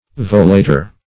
volator - definition of volator - synonyms, pronunciation, spelling from Free Dictionary
[1913 Webster] The Collaborative International Dictionary of English v.0.48: Volator \Vo*la"tor\, n. [NL.]